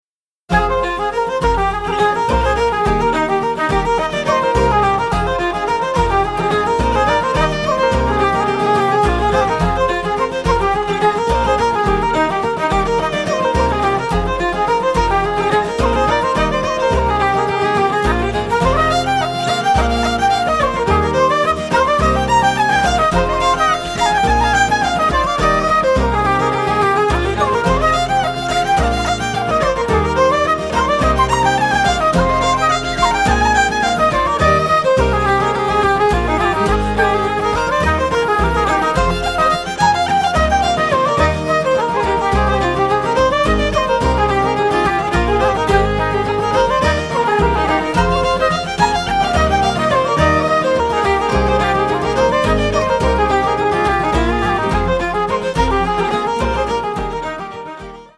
Fiddle
Flute
Guitars
Bouzouki and Bodhran